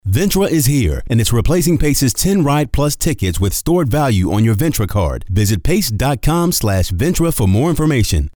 Smooth Bassy Voice Very articulate, Very Clear!
Sprechprobe: Werbung (Muttersprache):